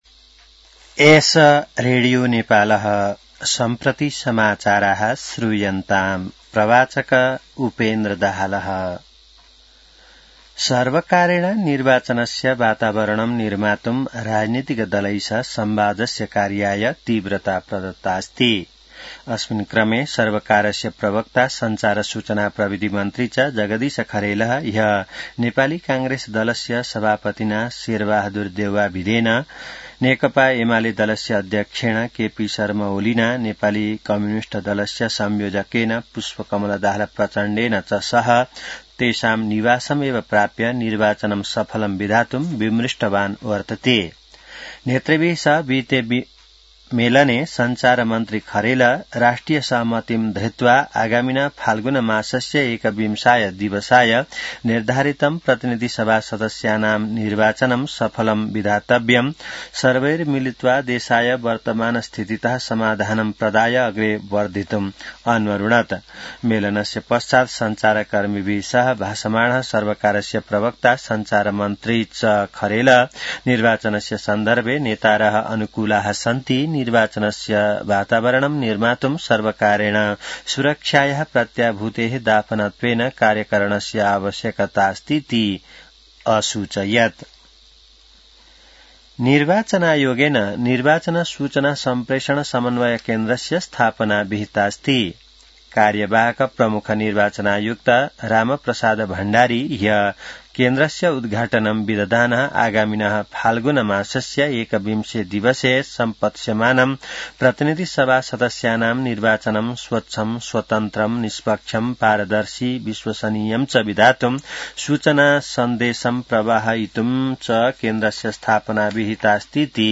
संस्कृत समाचार : १२ पुष , २०८२